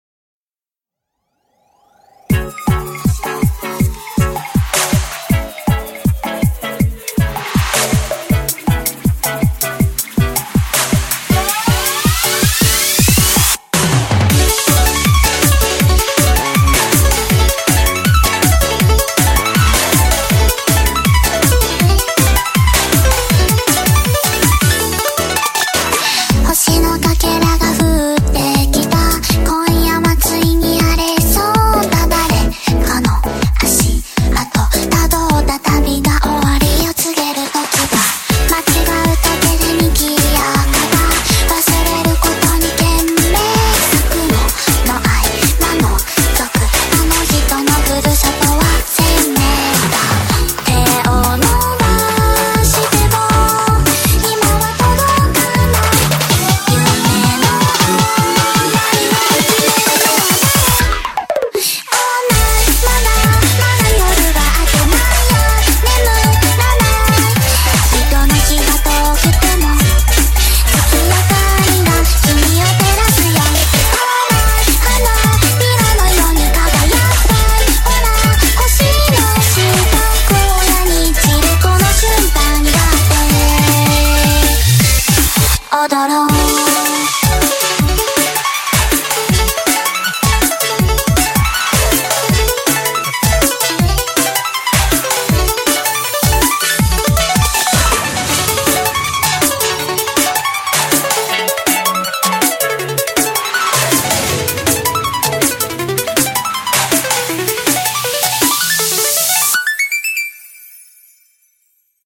BPM160
Audio QualityMusic Cut